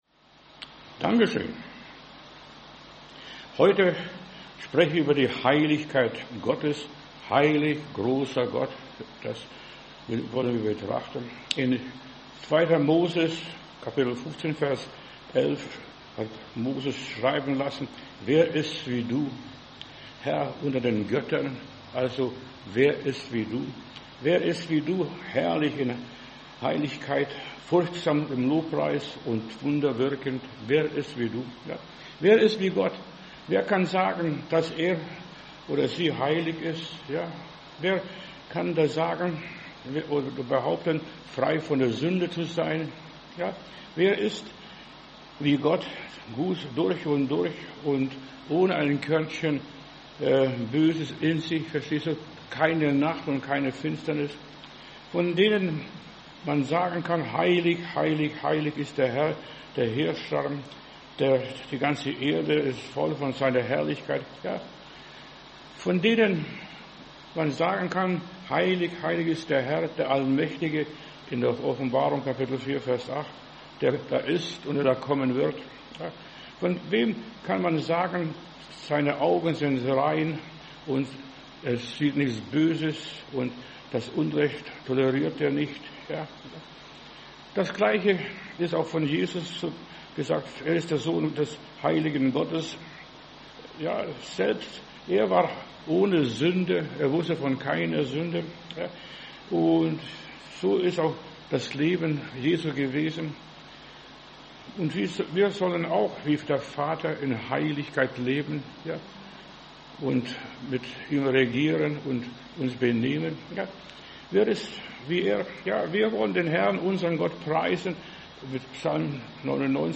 Predigt herunterladen: Audio 2025-07-09 Heiligkeit Gottes Video Heiligkeit Gottes